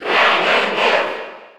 Category:Crowd cheers (SSB4) You cannot overwrite this file.
Ganondorf_Cheer_Spanish_PAL_SSB4.ogg